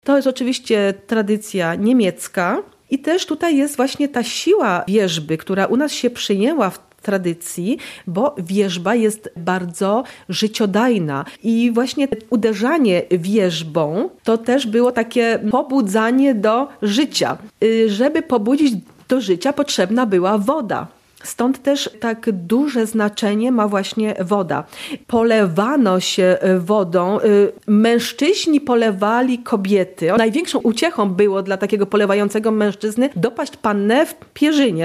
Etnograf wyjaśnia